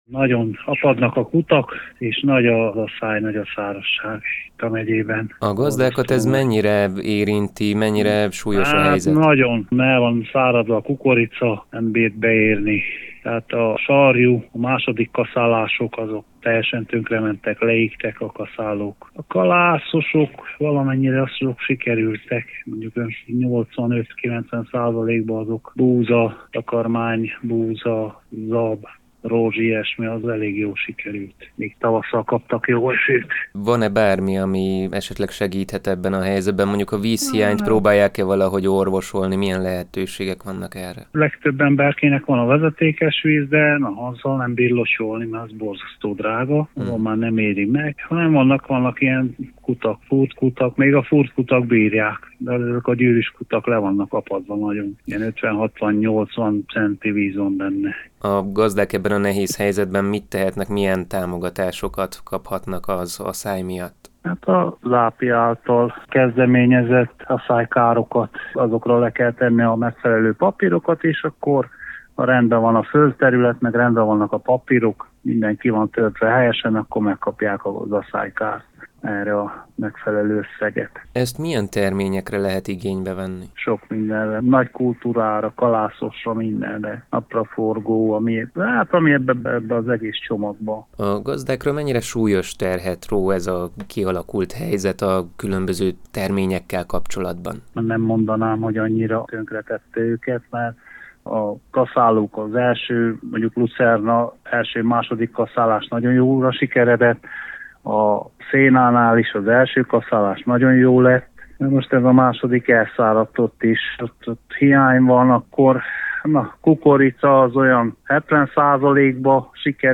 Falugazdásszal beszéltük át a kialakult aszályhelyzetet.